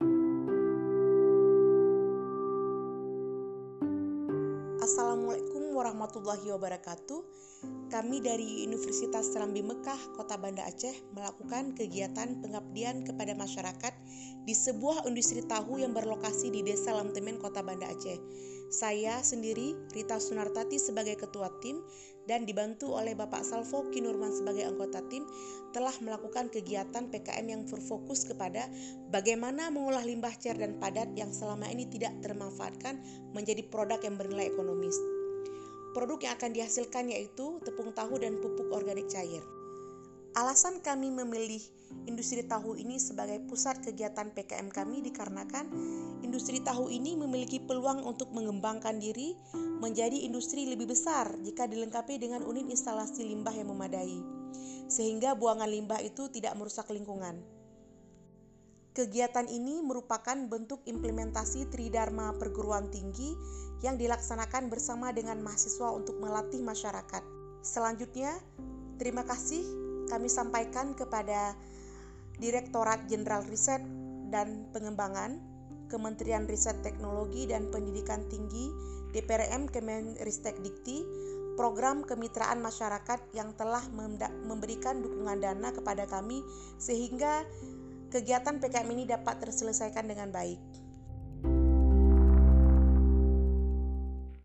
Format : Talk, music
Gaya penyiar   : Dewasa, dan smart